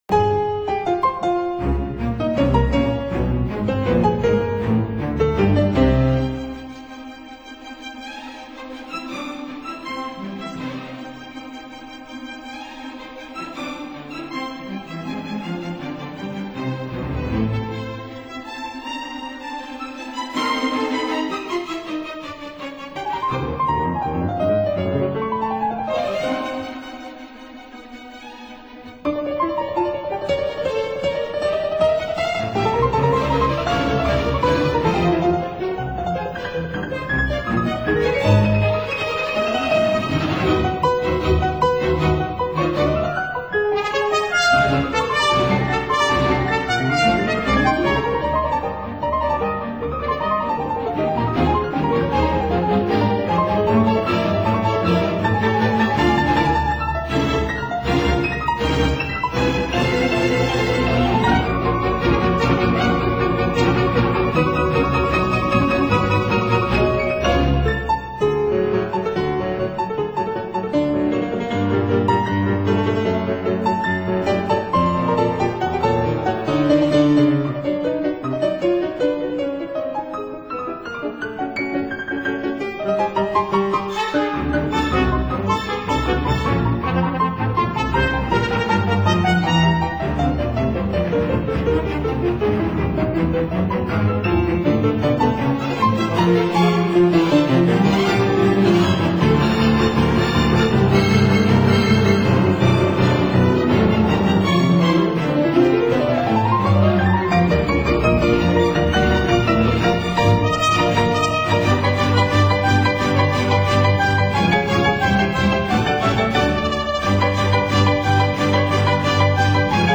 Suite for piano and orchestra
Concerto No. 2 for piano and orchestra
piano
trumpet